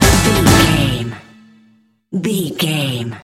Ionian/Major
synthesiser
drum machine
Eurodance